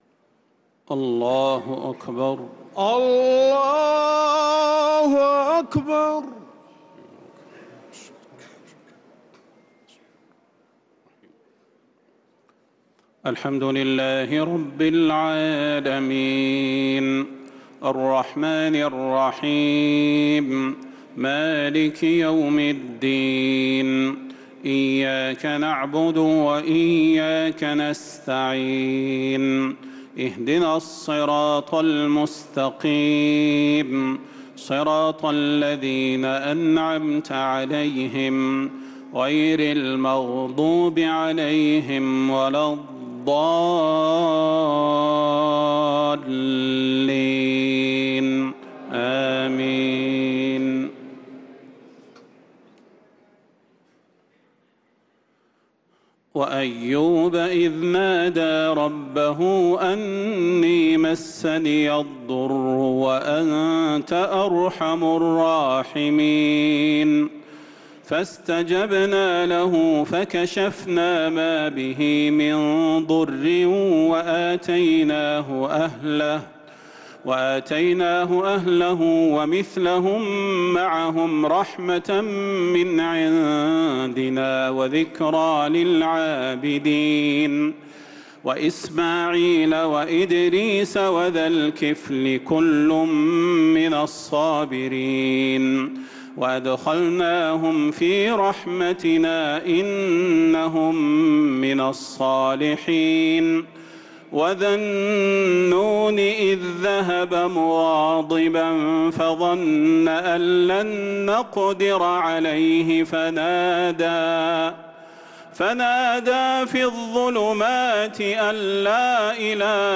تهجد ليلة 21 رمضان 1446هـ من سورتي الأنبياء (83-112) و الحج (1-37) | Tahajjud 21st night Ramadan 1446H Surah Al-Anbiya and Al-Hajj > تراويح الحرم النبوي عام 1446 🕌 > التراويح - تلاوات الحرمين